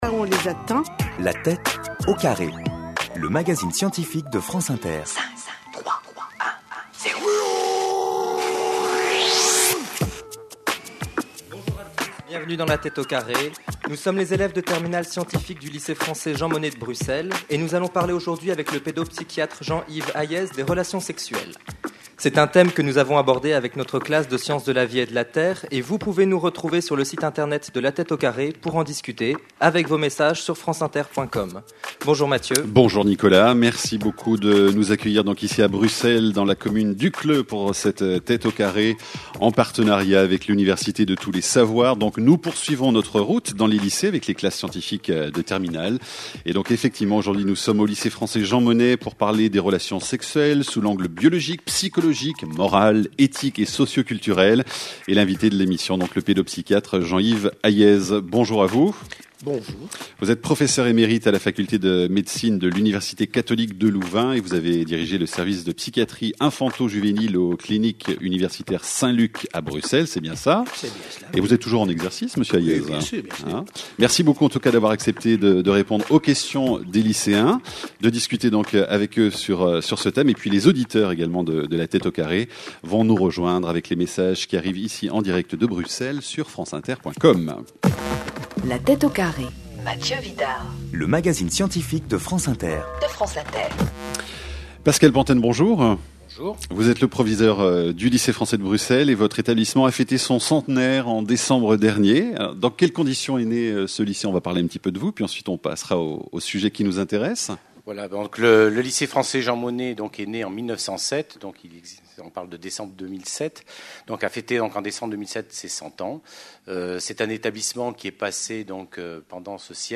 Une conférence de l'UTLS au Lycée : Y-a-t-il un âge pour les relations sexuelles ?